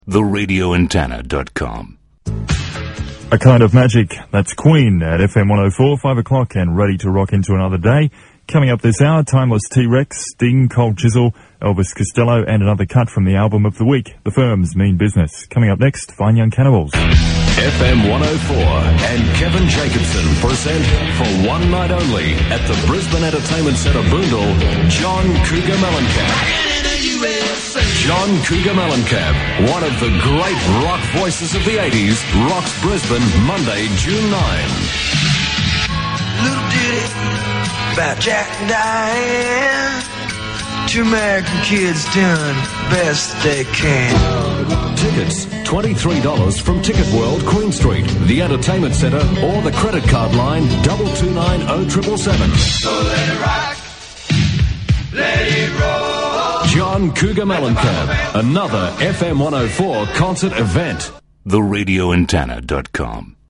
the start of this clip features former announcer